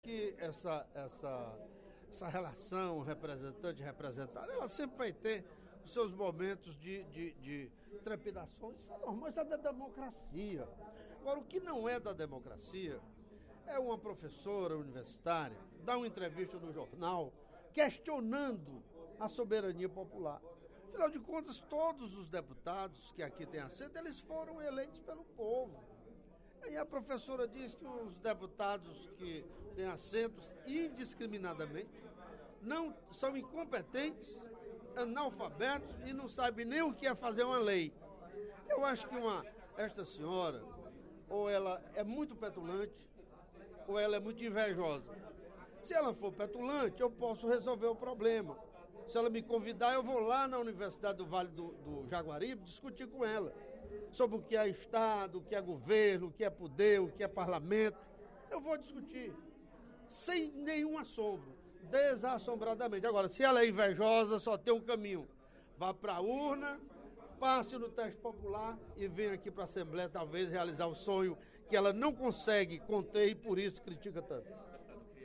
Em aparte, o deputado Ferreira Aragão (PDT) comentou que muitos especialistas em Ciência Política fazem avaliações sem conhecer a atividade parlamentar.